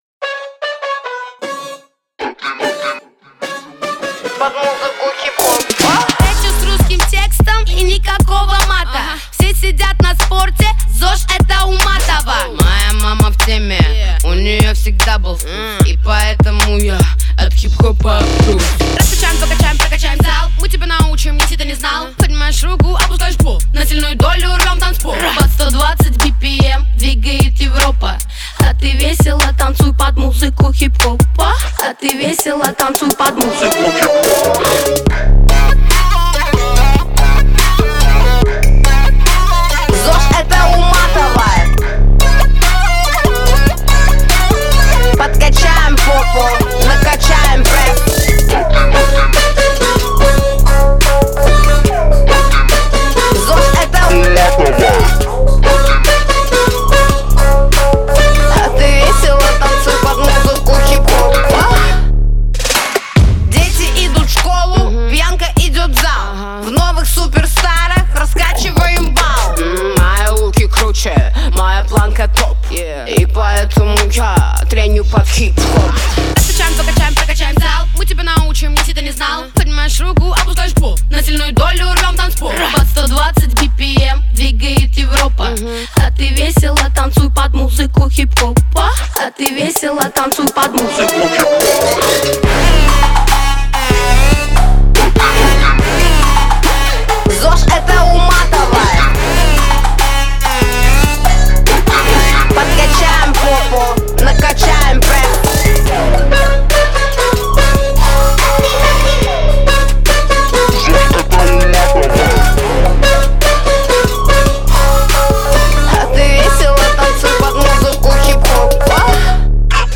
Энергичный трек о любви к хип-хопу и здоровому образу жизни.
Песня наполнена позитивной энергией и призывом к действию.